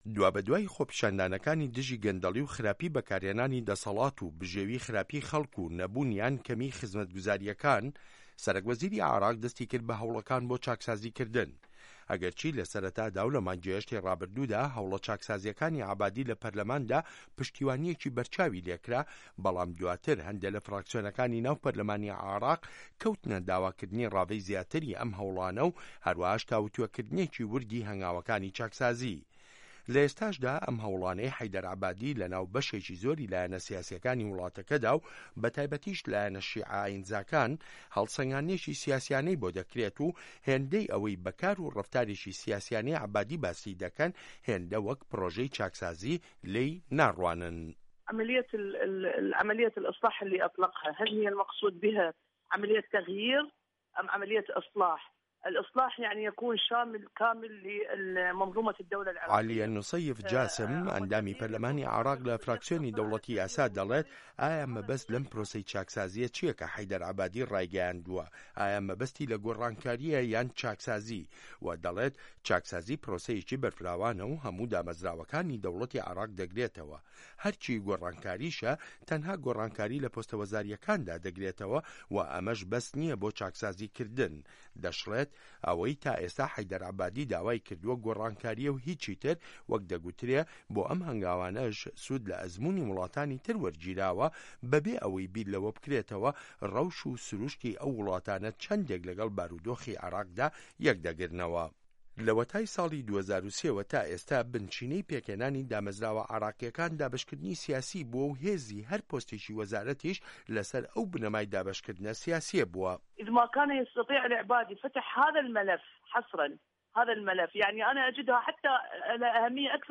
ڕاپۆرت لەسەر بنچینەی لێدوانەکانی عالیە نوسەیف جاسم